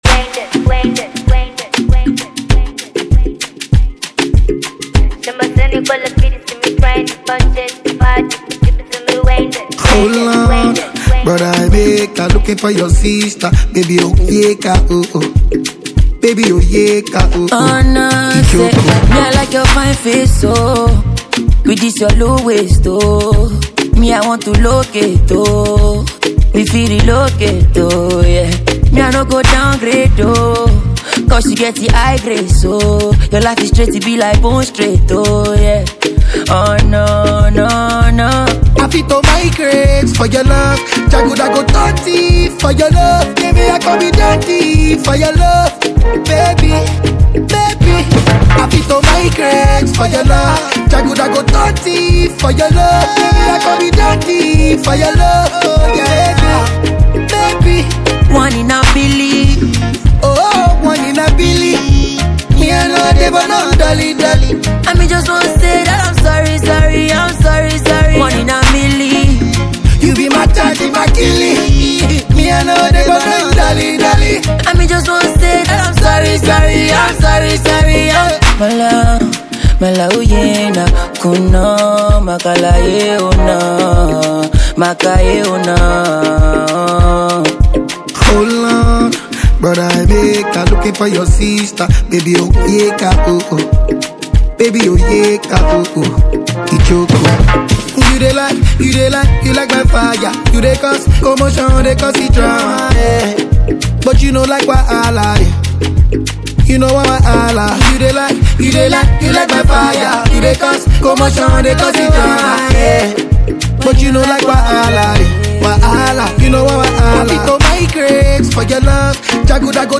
Buzzing fast-rising Nigerian singer